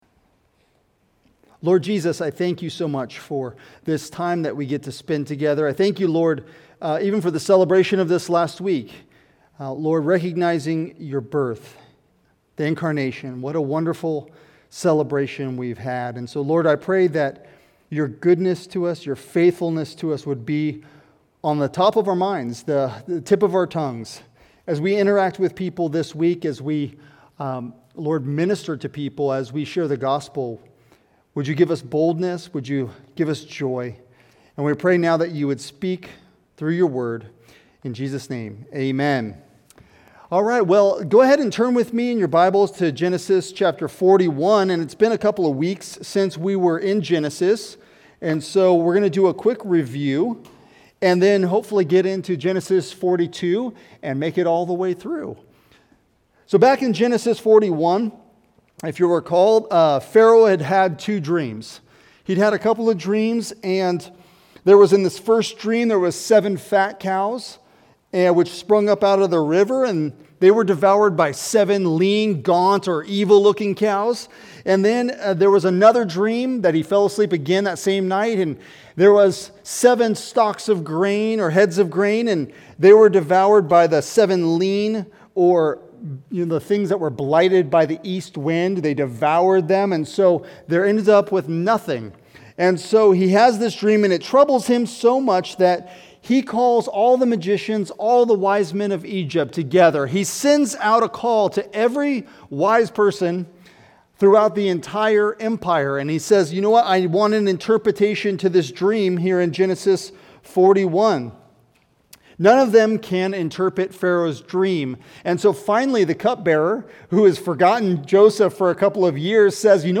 Sermons | Heritage Bible Church of Tri Cities